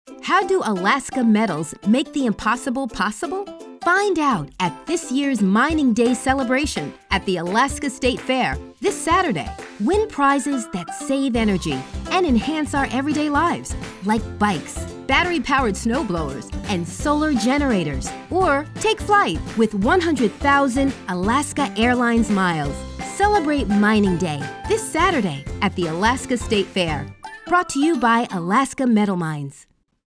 Friendly, Smart and Engaging.
Standard American, New York (Long Island)
Young Adult
Middle Aged
AKM State_Fair_Radio_Final.mp3